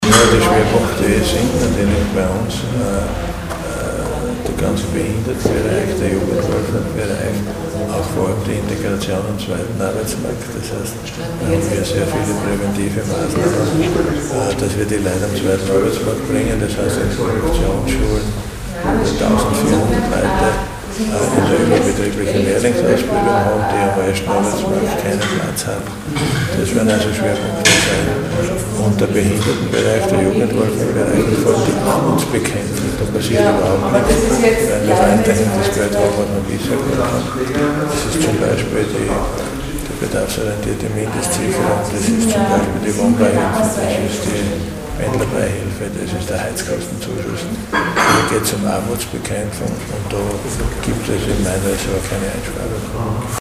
O-Ton: Budgetpräsentation Edlinger-Ploder und Schrittwieser
Oktober 2012).-  Heute (11.10.2012) präsentierten die beiden Landesräte Kristina Edlinger-Ploder und Siegfried Schrittwieser im Medienzentrum Steiermark ihre Ressortdoppelbudgets für die Jahre 2013 und 2014.